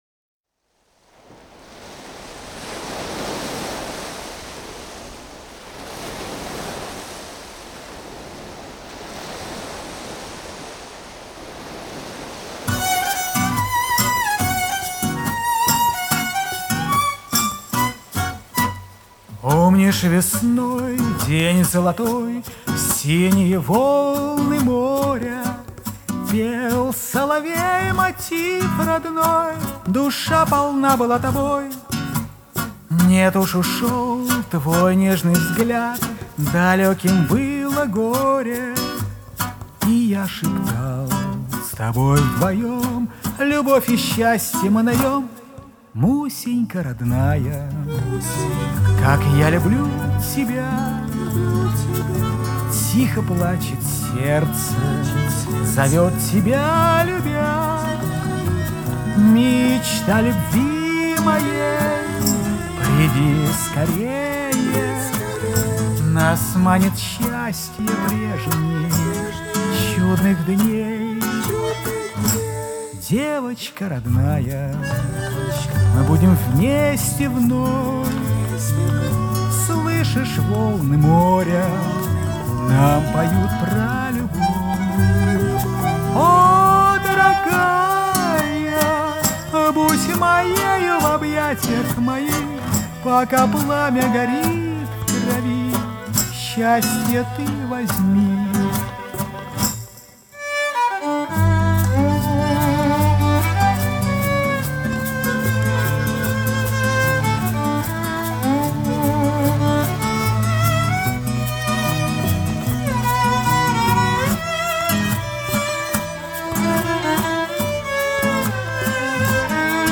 Дуэт двух мульти-инструменталистов
Жанр: Фолк, Шансон, Романс, Акустика